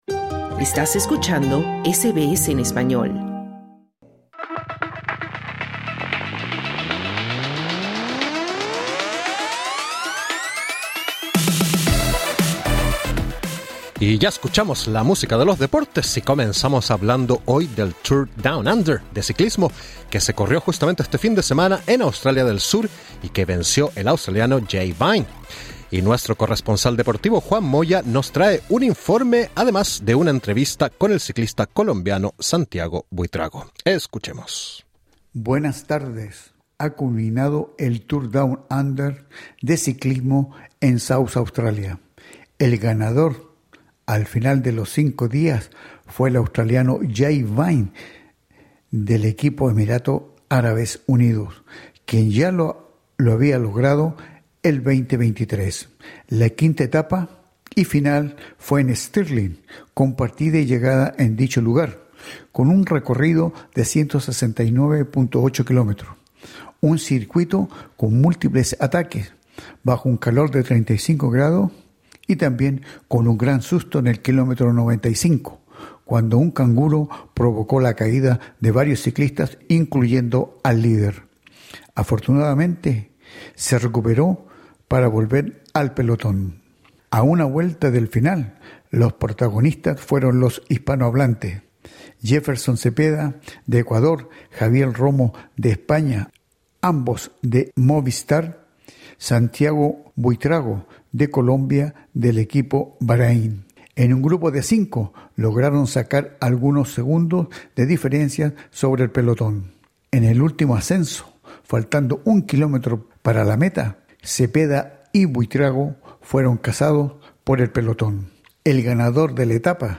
Conversamos con el ciclista colombiano Santiago Buitrago, quien formó parte de esta carrera.